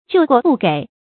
救過不給 注音： ㄐㄧㄨˋ ㄍㄨㄛˋ ㄅㄨˋ ㄍㄟˇ 讀音讀法： 意思解釋： 猶救過不贍。